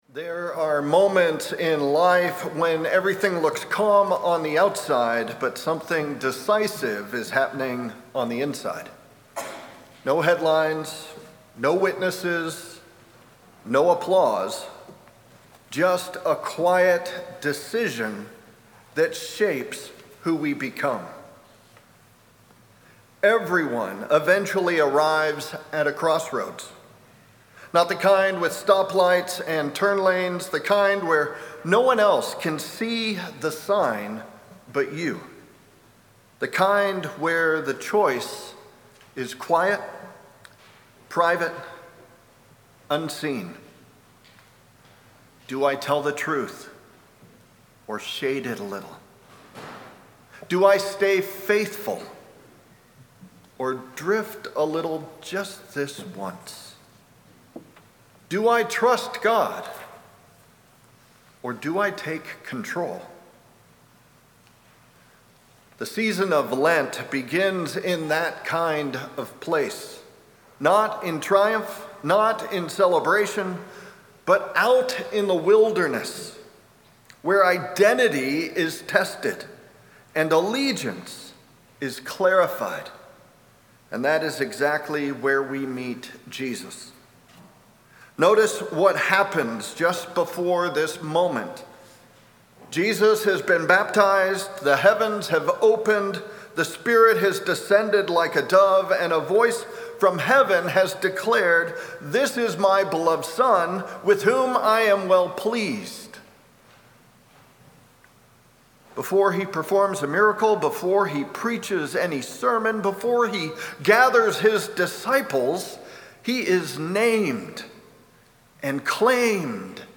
Audio Sermons details